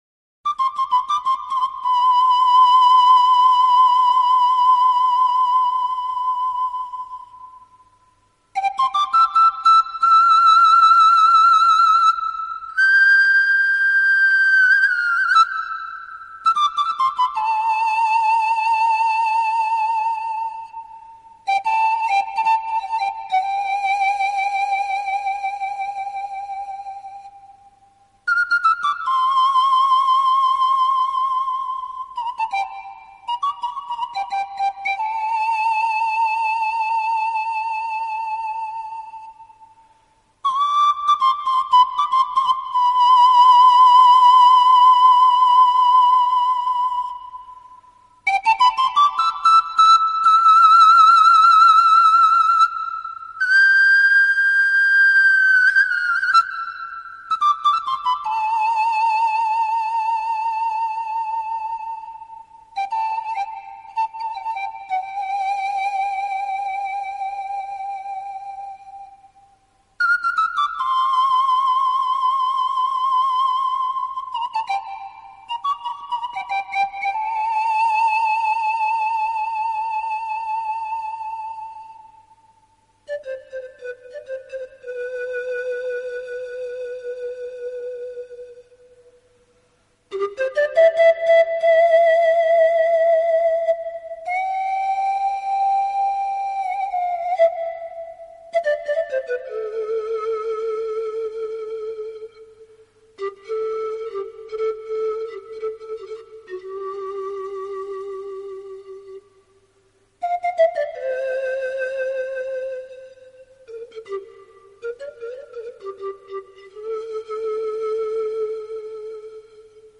超广角音场的空间感演绎，大自然一尘不染的精华，仿佛让你远离凡尘嚣暄，